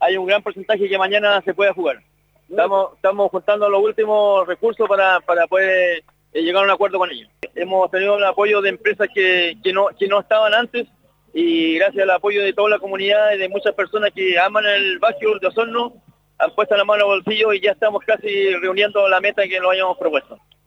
En conversación con Radio Sago en el lugar, dijo que estarían muy cerca de llegar a la meta de los 16 millones de pesos, lo que se traduciría en que el equipo sí se presentaría a jugar ante CEB Puerto Montt este fin de semana en la lucha por mantenerse en la primera división del básquetbol.